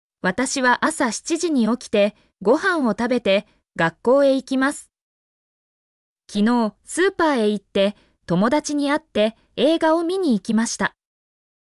mp3-output-ttsfreedotcom-45_gZoMluUX.mp3